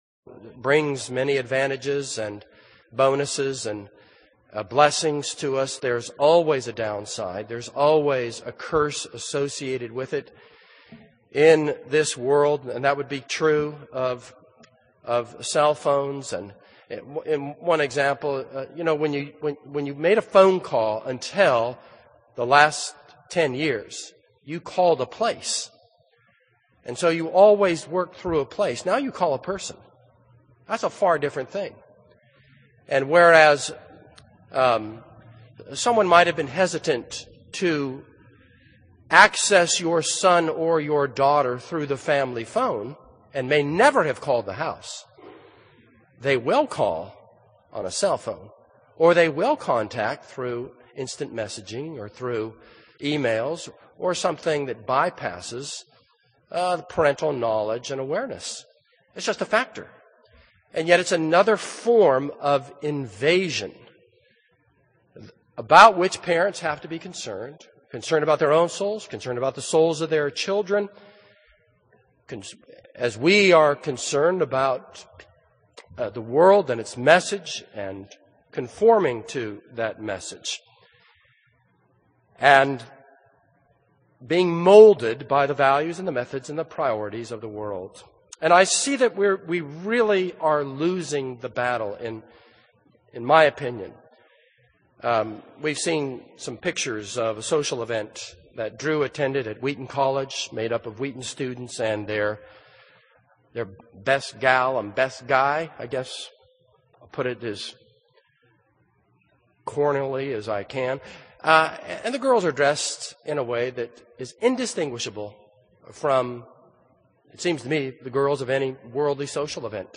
This is a sermon on 2 Kings 17:1-23.